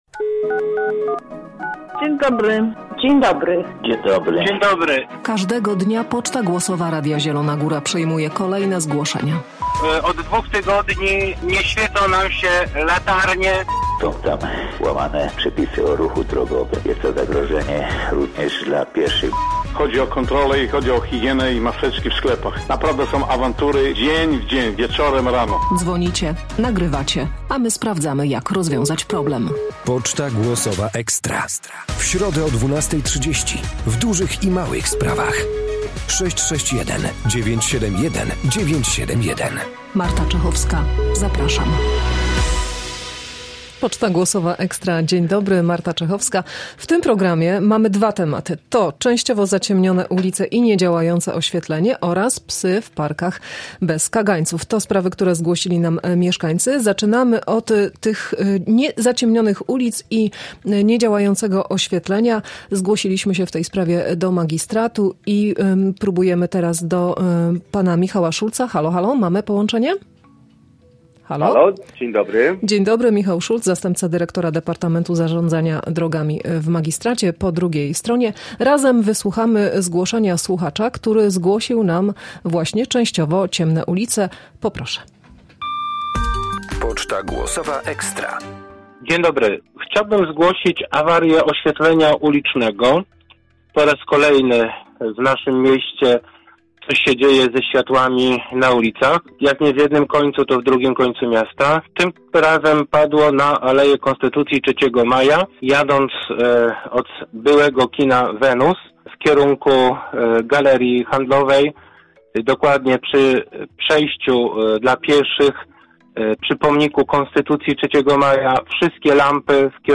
O kwestie awarii oświetlenia ulicznego i wymiany lamp w mieście zapytaliśmy urzędników. Z kolei przedstawiciele zielonogórskiej policji odpowiadali na zgłoszenie słuchacza o psach, które – jak mówił – na spacerach w parkach nie mają kagańców.